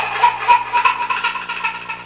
Baby Choking Sound Effect
Download a high-quality baby choking sound effect.
baby-choking.mp3